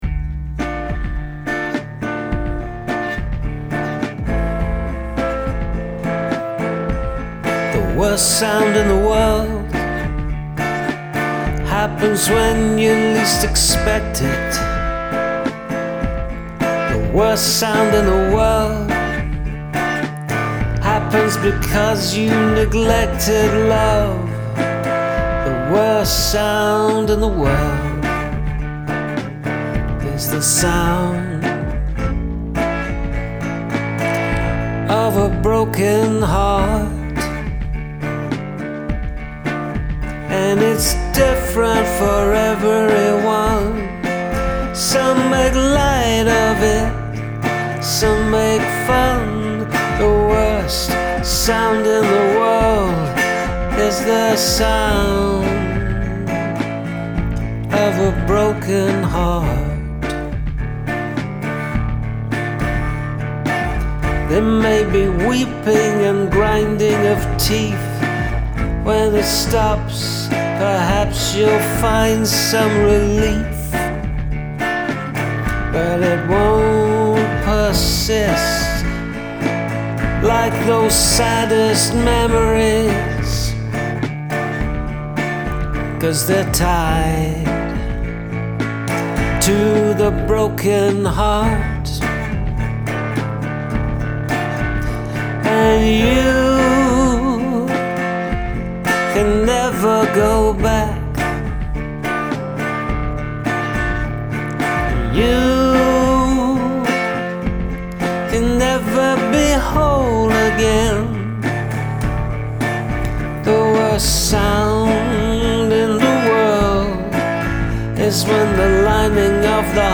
Sweet melody.
It has a melancholy about it.